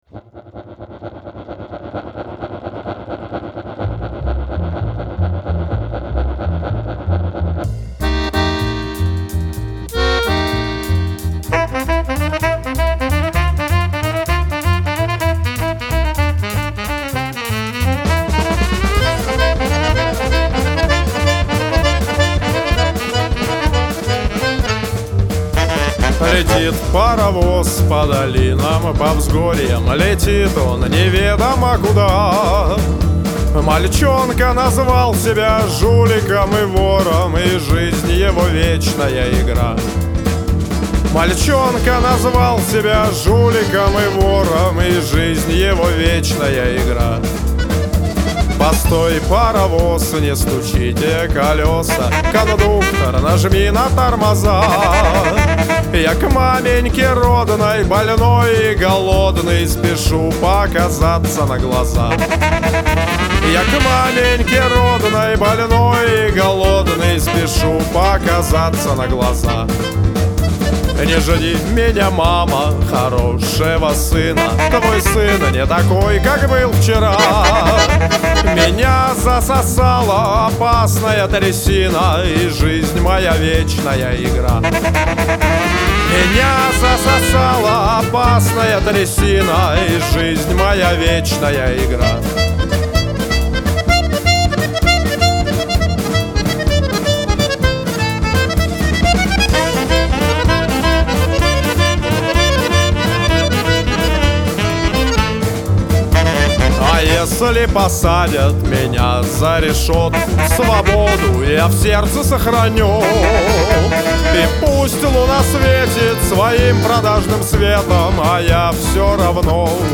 Такого качества Одесского фольклора я ещё не слышал.